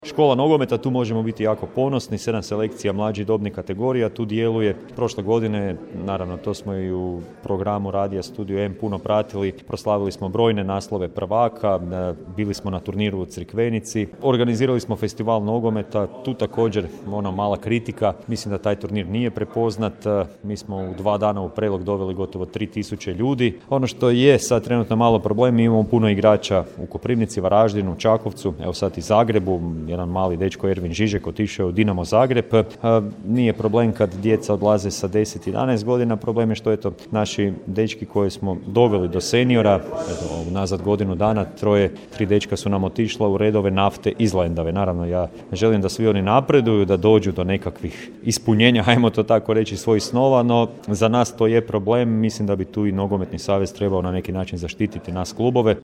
NK Mladost Komet, redovna godišnja skupština kluba, 10.2.2023. / Poduzetnički centar Prelog